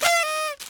CosmicRageSounds/partyhorn.ogg_28735af5 at 4f151c074f69b27e5ec5f93e28675c0d1e9f0a66